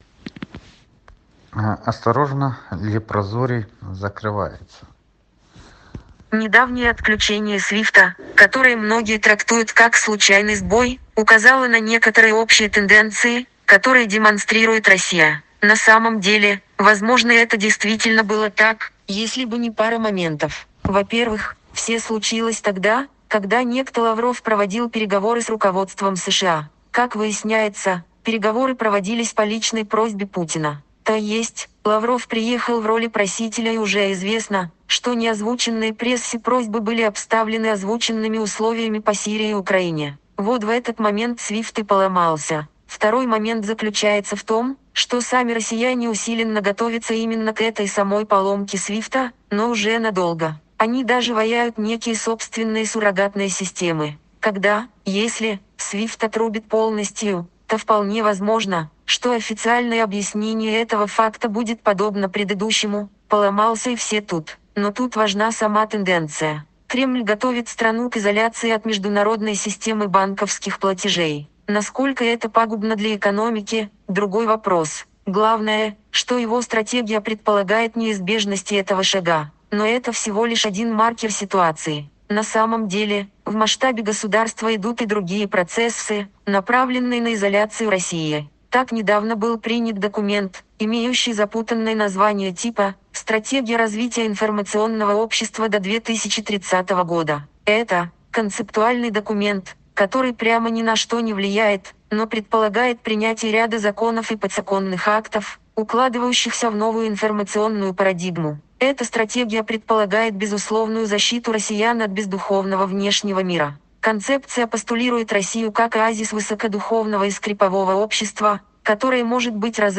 Аудиоверсия “Линия Обороны. Осторожно, лепрозорий закрывается” (Siri) –